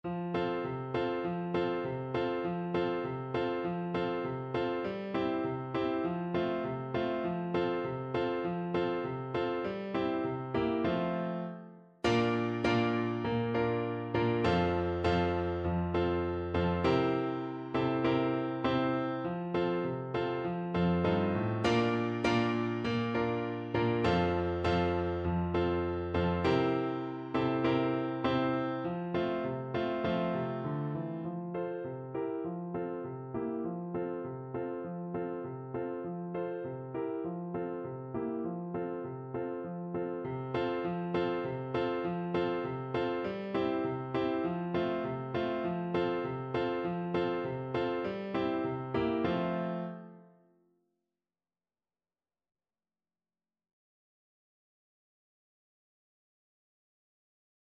~ = 100 Allegro (View more music marked Allegro)
2/4 (View more 2/4 Music)
C4-D5
Traditional (View more Traditional French Horn Music)
Scouting Songs for French Horn